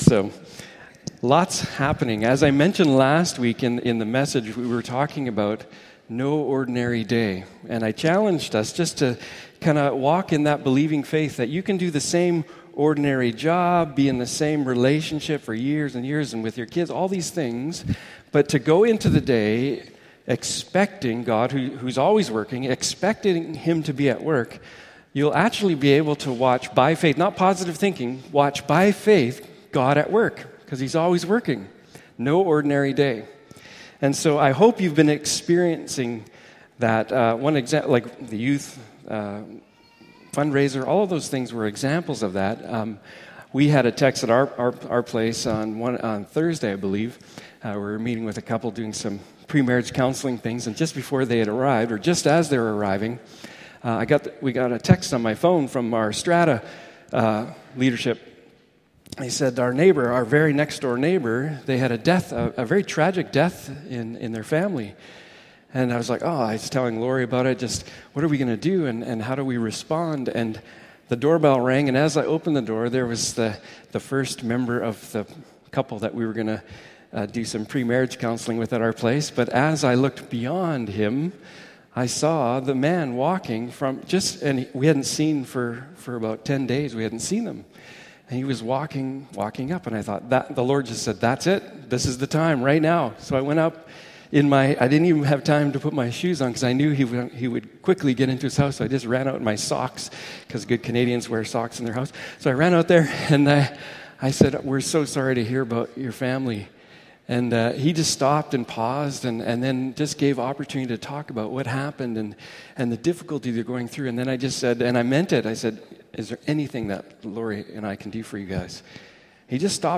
The Unfinished Story Passage: Acts 3 Service Type: Morning Service « Acts of Jesus